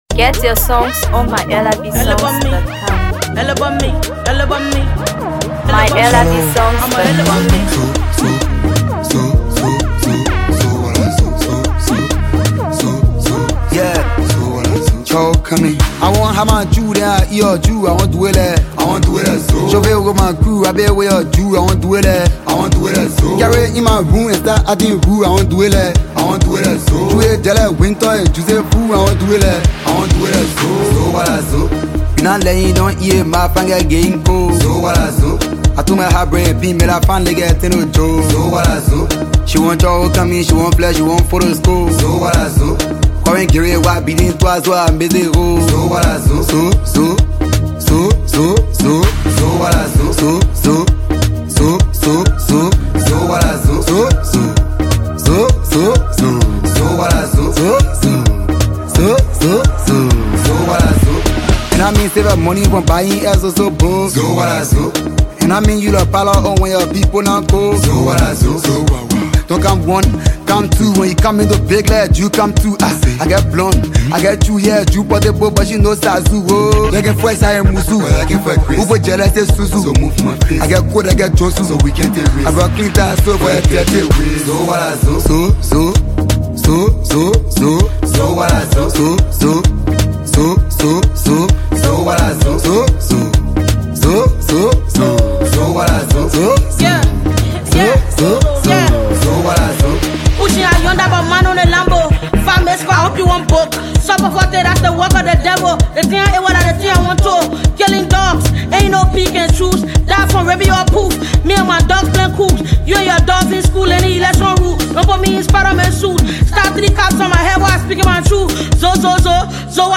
is a vibrant Afrobeat fusion